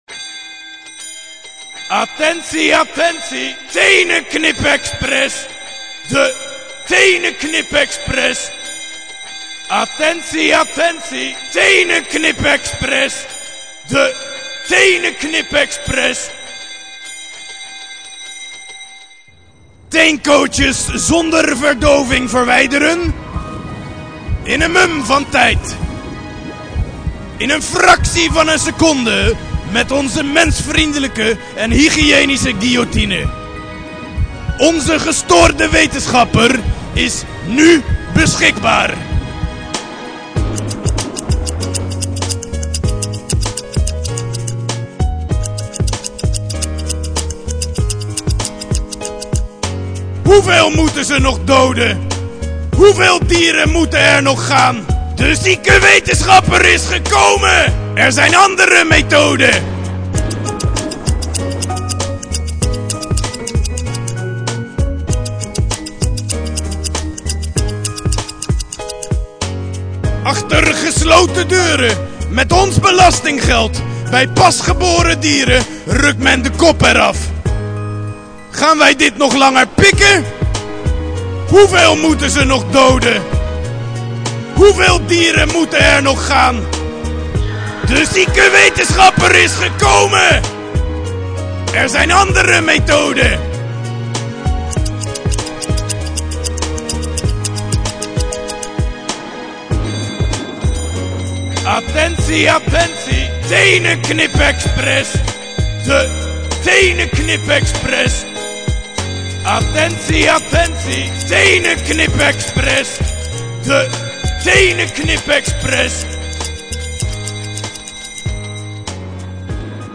Voorzien van een geluidswagen met een hippe jingle, gekleed in met 'bloed' besmeurde pakken en mondkapjes gingen de dierenvrienden op bezoek bij beide laboratoria.
Jingle !?